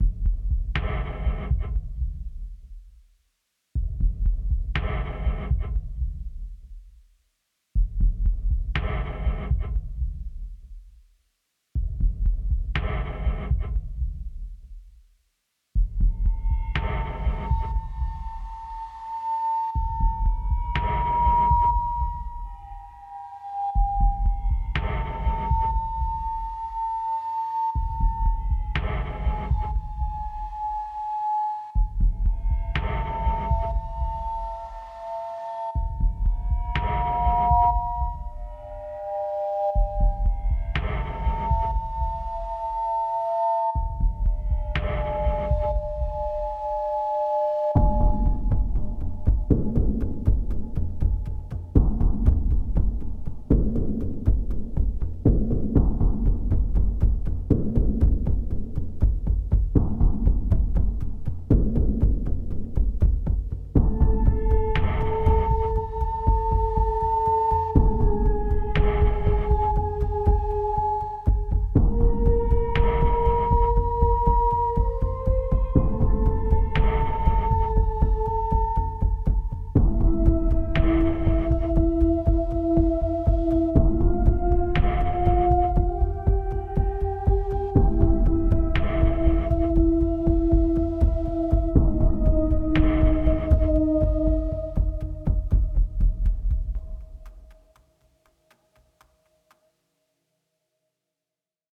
tension horror soundscapes